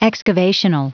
Prononciation du mot excavational en anglais (fichier audio)